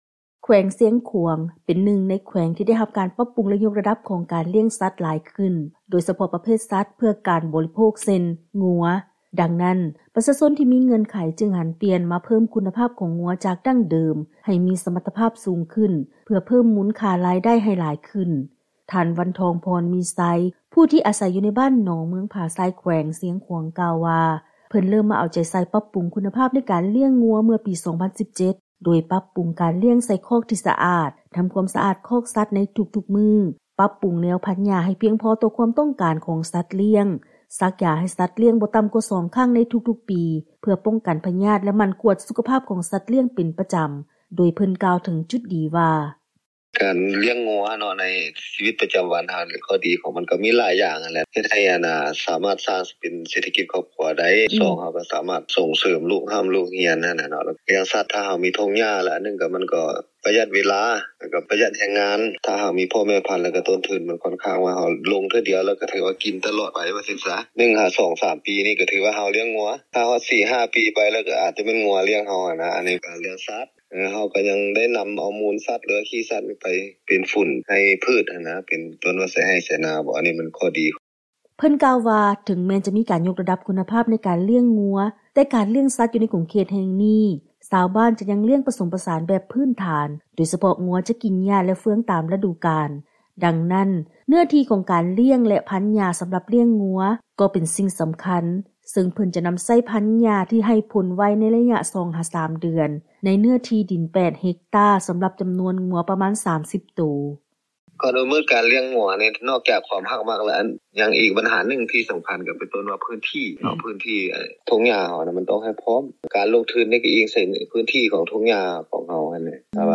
ເຊີນຟັງລາຍງານກ່ຽວກັບ ການສ້າງລາຍໄດ້ ໂດຍການຍົກຄຸນນະພາບການລ້ຽງງົວເພື່ອສົ່ງອອກ